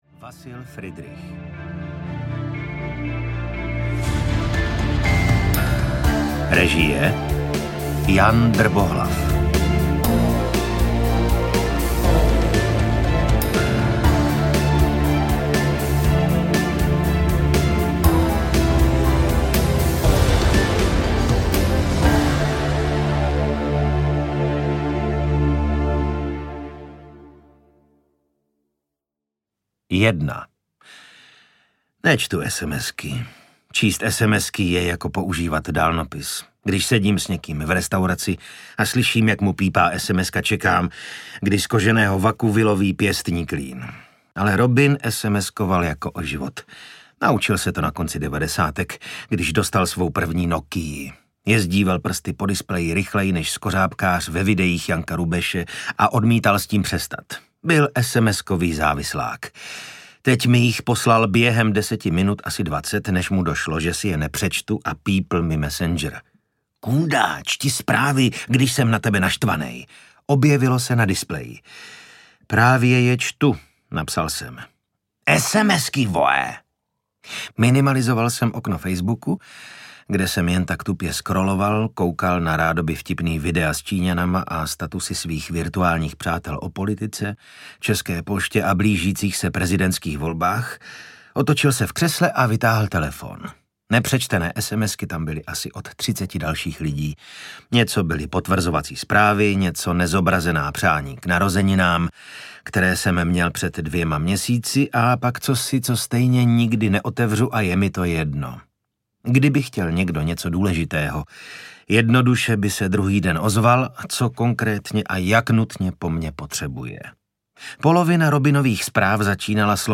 Syndikát audiokniha
Ukázka z knihy
Nový detektivní příběh z pera autora Sudetenlandu ožívá i jako audiokniha v podání Vasila Fridricha. Zaposlouchejte se do příběhu z budoucnosti tak blízké, že než knihu dočtete, určitě se stane.
• InterpretVasil Fridrich